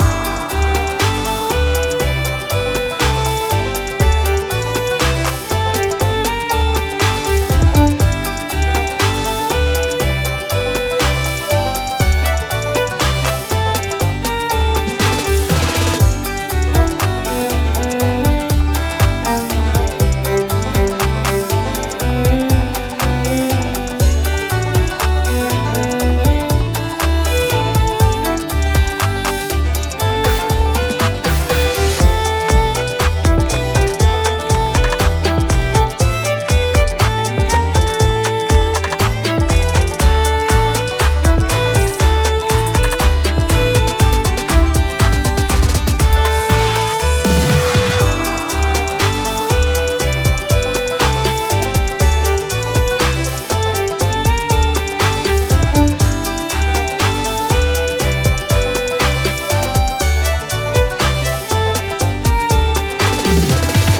Violin player